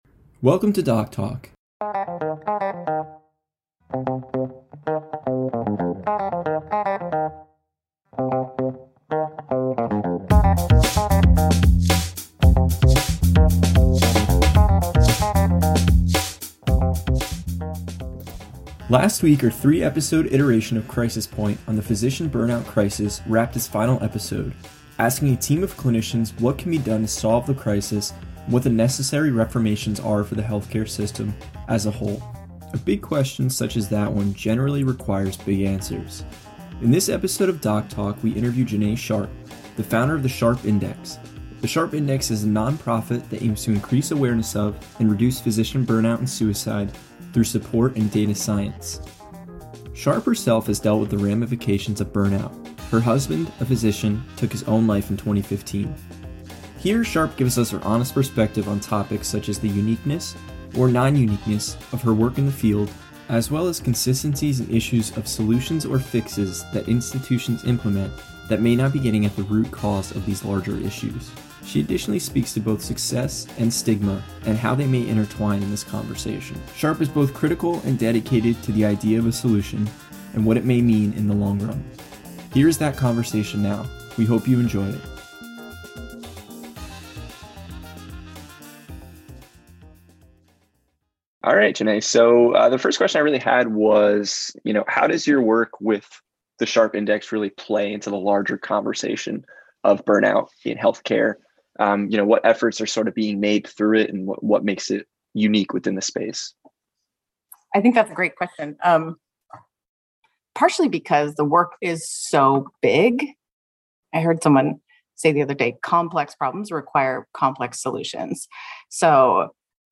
In this episode of DocTalk, we interview